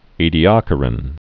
(ēdēkə-rən)